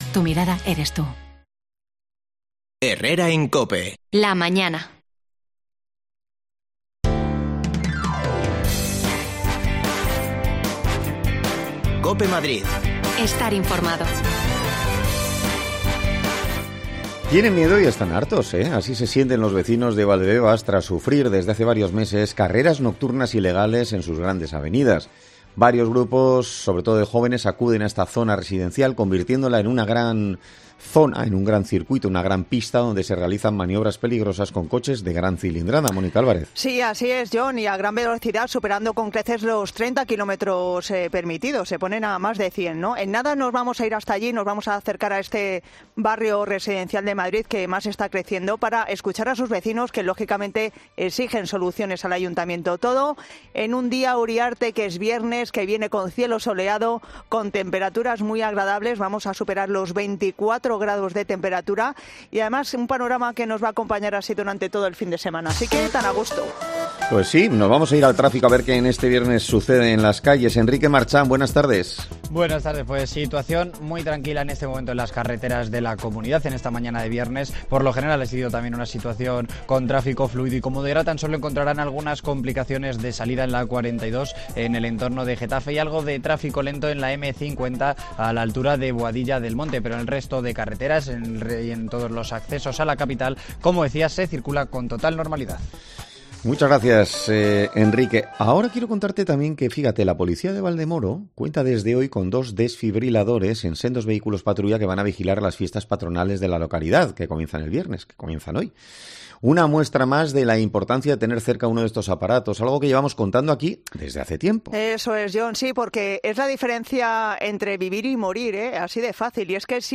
Los vecinos de Valdebebas están preocupados por las carreras ilegales nocturnas que se producen en sus calles desde hace varios meses. Nos acercamos alli para hablar con ellos